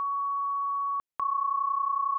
Listen to 1121 Hz pure tone followed by 1121 Hz and 1090 Hz combination tone
The roughness of the complex tone should be very obvious by listening to the tones above.